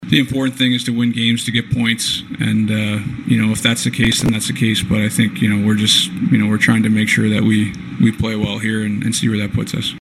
Crosby says the Penguins needed the points, as they are one point out of first place in a tightly-packed NHL East.